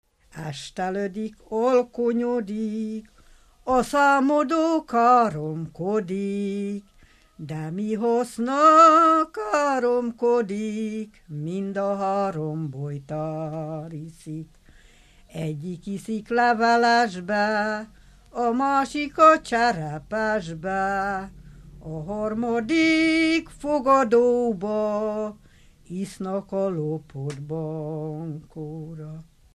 Dunántúl - Somogy vm. - Rinyakovácsi
Stílus: 1.2. Ereszkedő pásztordalok
Szótagszám: 8.8.8.8
Kadencia: 4 (b3) 4 1